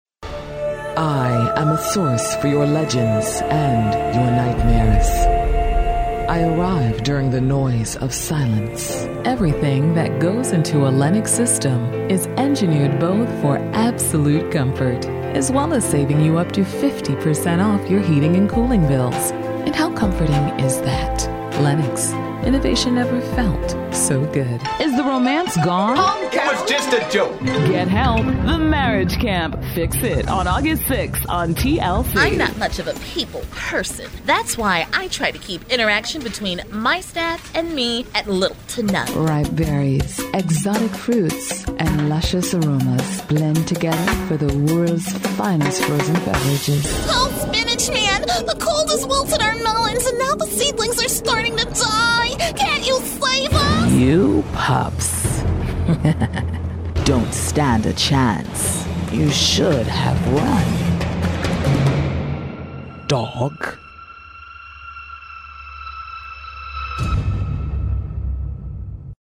englisch (us)
Sprechprobe: Werbung (Muttersprache):
A voice with true range, excellent natural characters, sympathetic, everyday person. My sound is also as sensual as melted chocolate. I am able to adjust my vocal style to the desired specifications of the client.